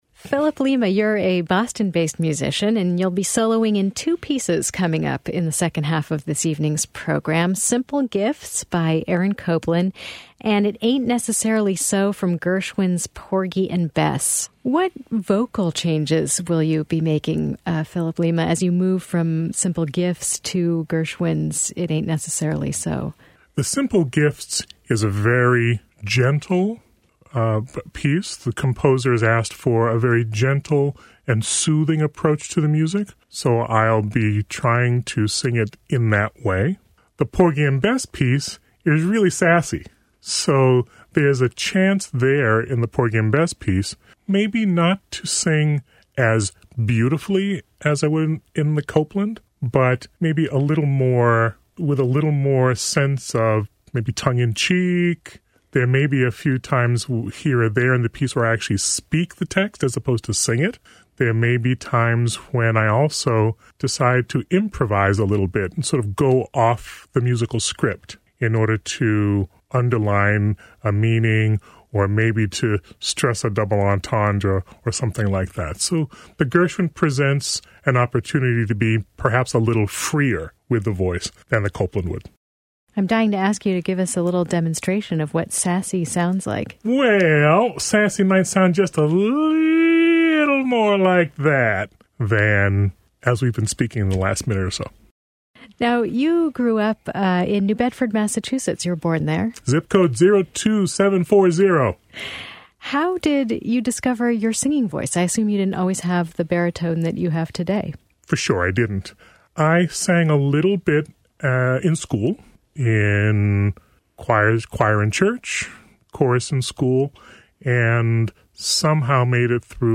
Rhode Island Public Radio and Latino Public Radio offered live broadcasts of the concert.